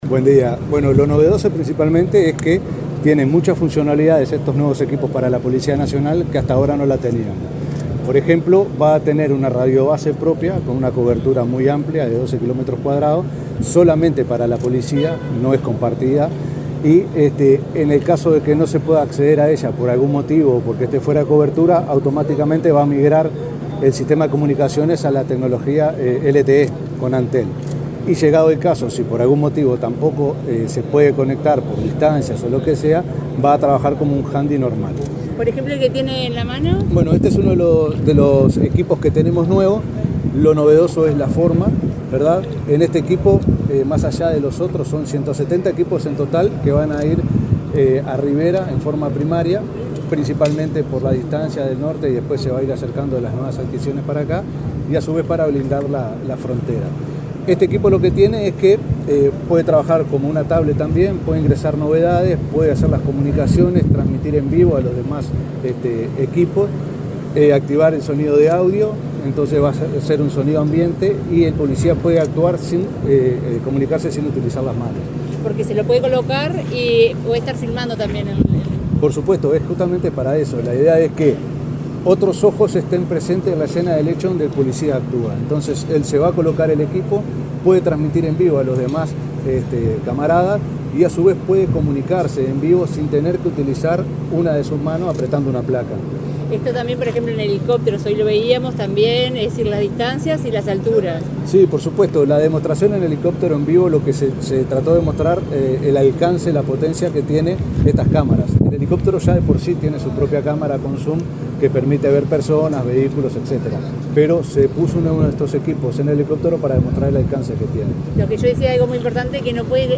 Declaraciones del director del Centro de Comando Unificado del Ministerio del Interior, Gabriel Lima
Declaraciones del director del Centro de Comando Unificado del Ministerio del Interior, Gabriel Lima 01/11/2024 Compartir Facebook X Copiar enlace WhatsApp LinkedIn El director del Centro de Comando Unificado del Ministerio del Interior, Gabriel Lima, dialogó con la prensa, luego del acto de presentación de los nuevos equipos de comunicaciones para la Policía Nacional.